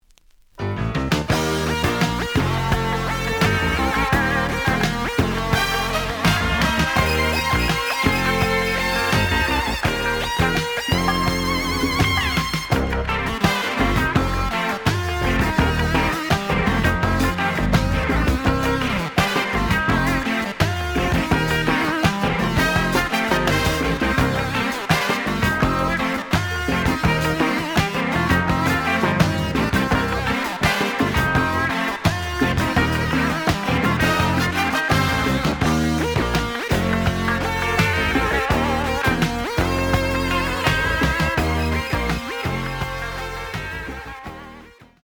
SOUL FUNK
試聴は実際のレコードから録音しています。
●Genre: Funk, 80's / 90's Funk